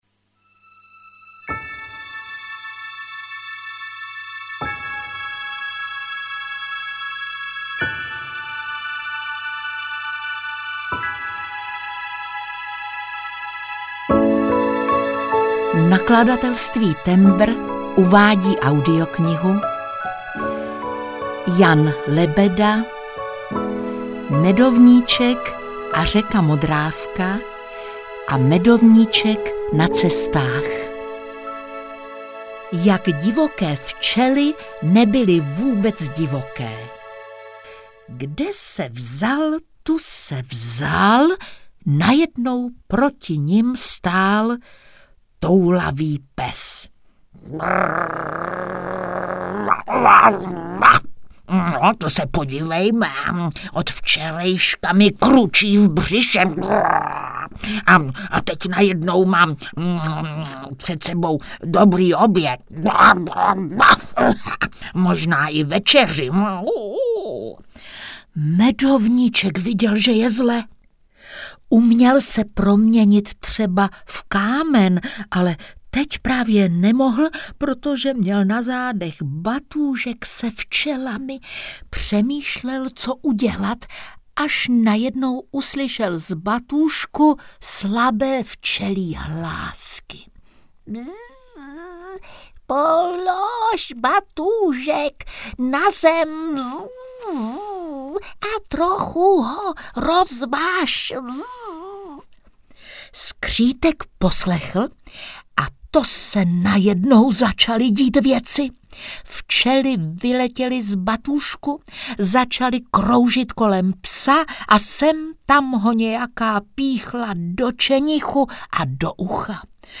Ukázka z knihy
• InterpretJitka Molavcová